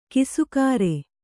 ♪ kisukāre